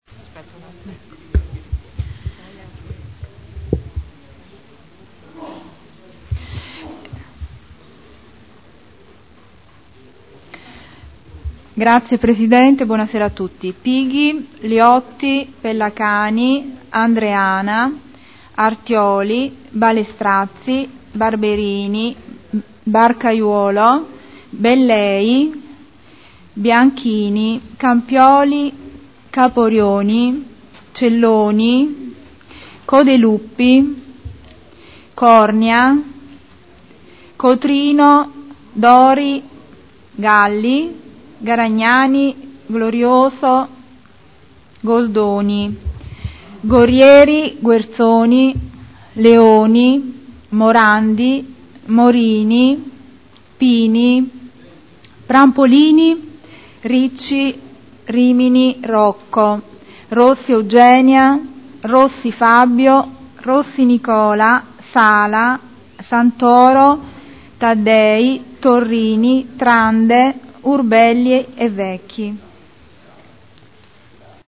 Seduta del 22/12/2011. Appello
Segretario generale